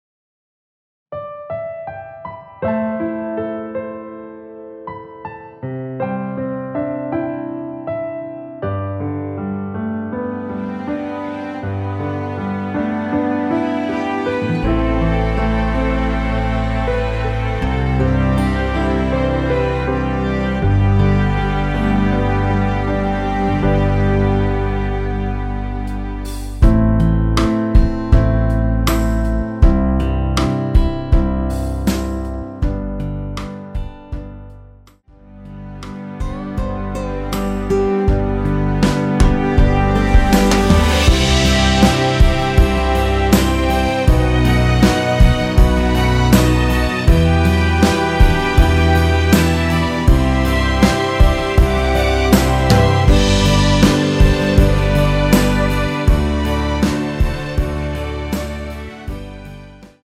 원키에서(+1)올린 MR입니다.
앞부분30초, 뒷부분30초씩 편집해서 올려 드리고 있습니다.